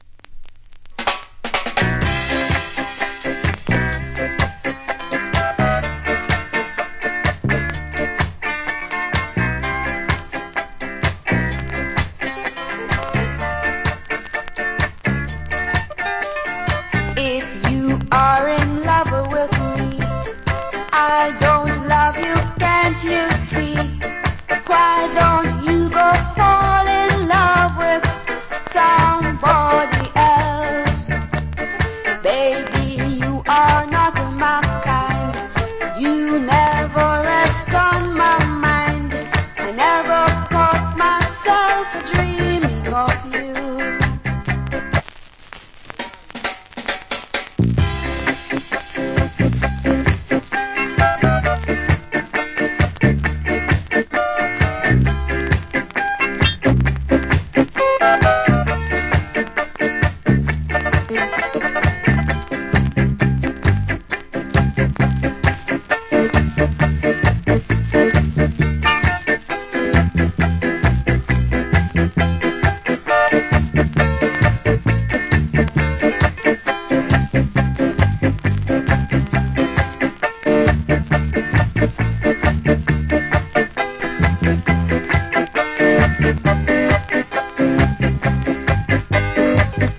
Nice Female Early Reggae Vocal.